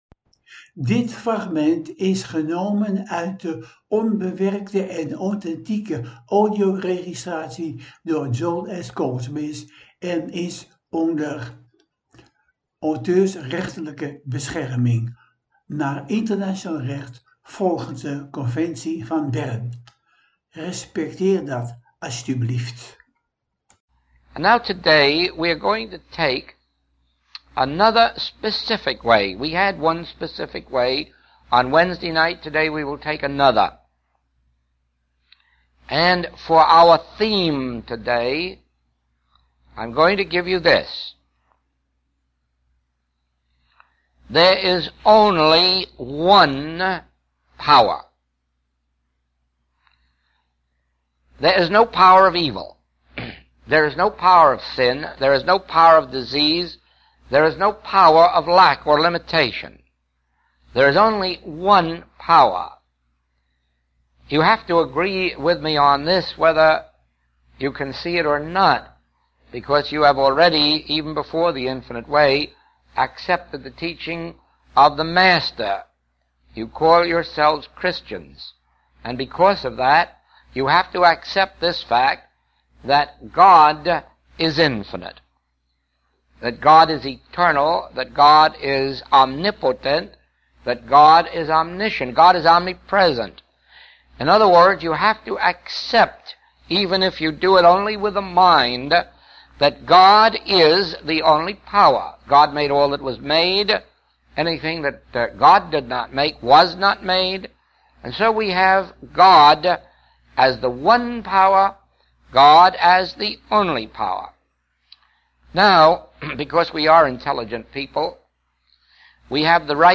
ENKELE FRAGMENTEN UIT DE AUTHENTIEKE, ONBEWERKTE AUDIOREGISTRATIES